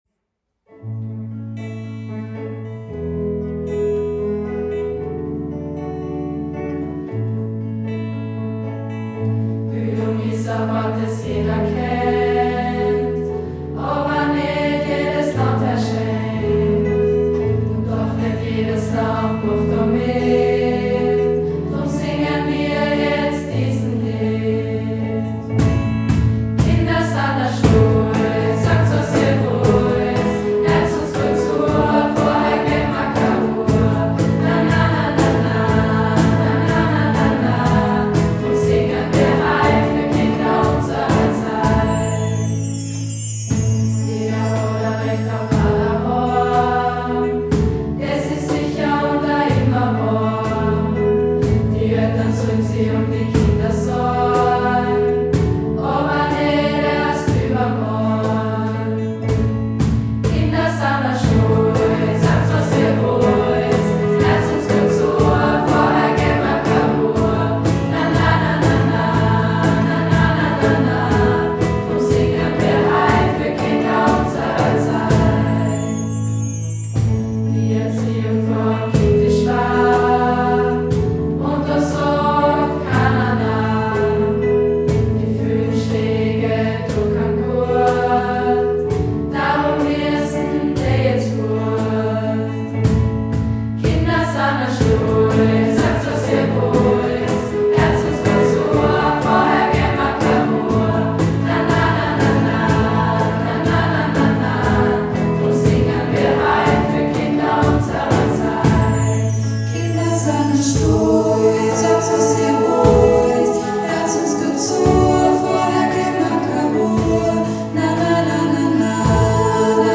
Und weil wir zukünftige Kindergartenpädagoginnen sind, fühlen wir uns verpflichtet, über die Rechte der Kinder zu singen.